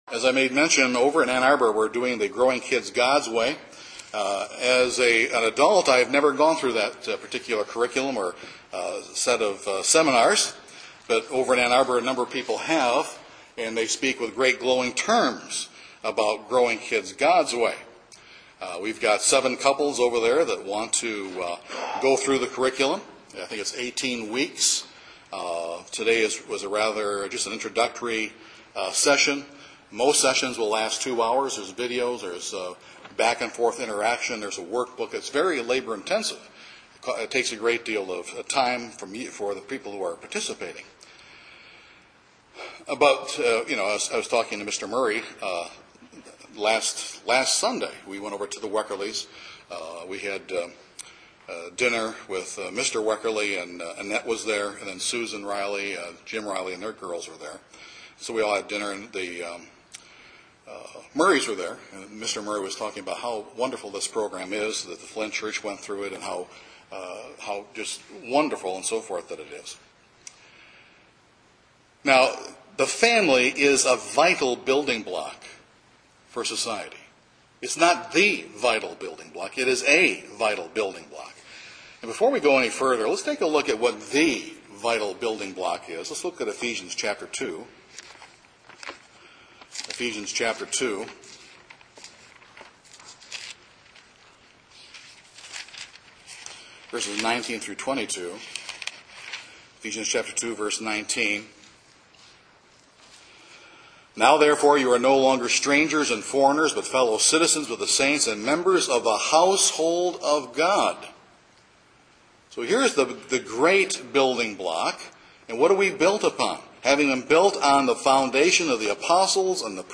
Parents within God’s Church desire for their children to have a loving, nurturing home environment, but how does that come to be, specifically? This sermon is for the fathers and will be followed by another full sermon for the mothers. Dad, here are some practical as well as biblical principles for you to employ so that you can furnish your children with the loving, nurturing environment they want and need.